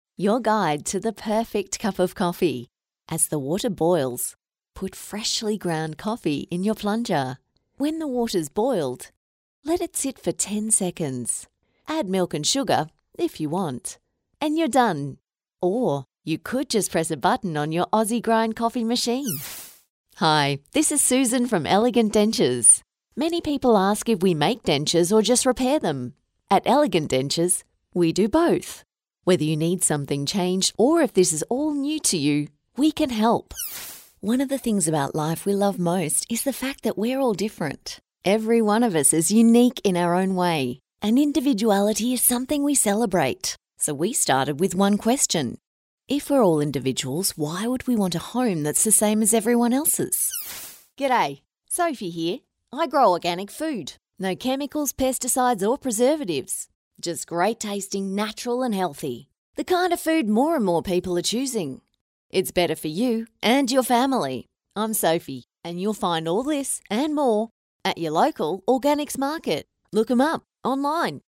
I’m an authentic Australian female voiceover artist with over 18 years of experience.
I can provide a quality studio recording from my home studio with a Rode NT1-A microphone and Adobe Audition editing software.
• Conversational